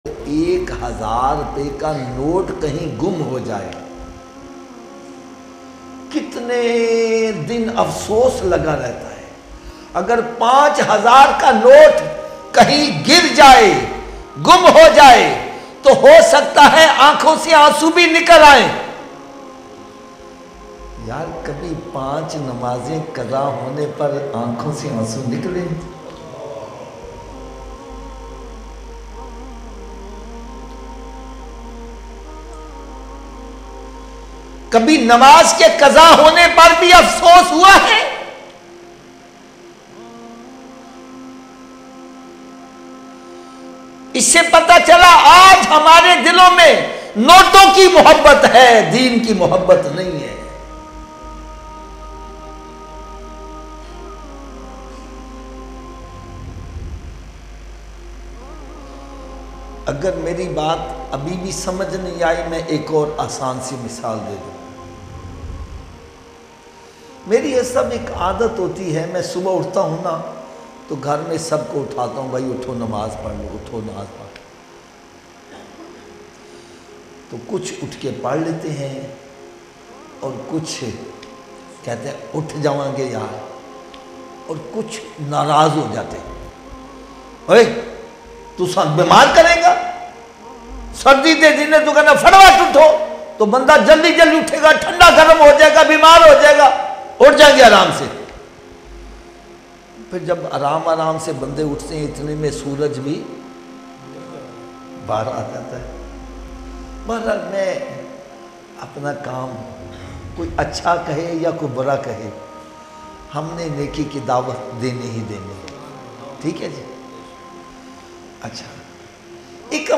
5000 ka note gum ho jaye - Heart Touching Bayan